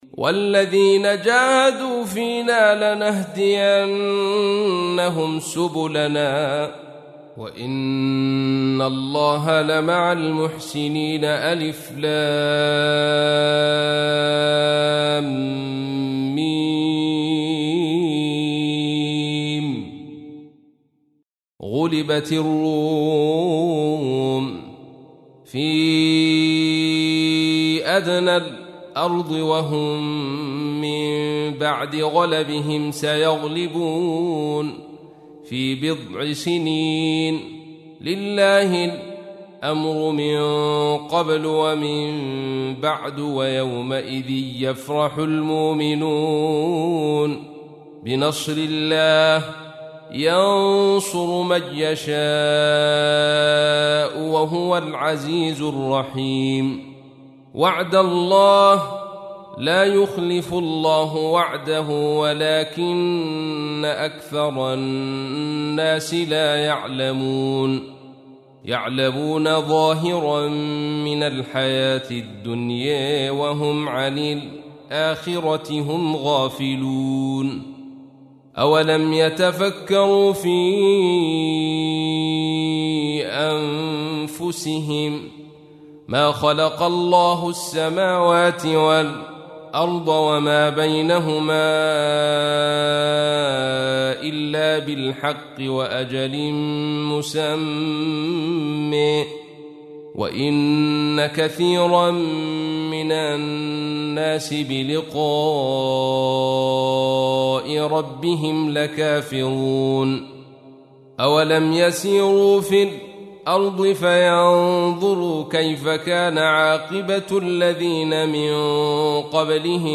تحميل : 30. سورة الروم / القارئ عبد الرشيد صوفي / القرآن الكريم / موقع يا حسين